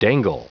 Prononciation du mot dangle en anglais (fichier audio)
Prononciation du mot : dangle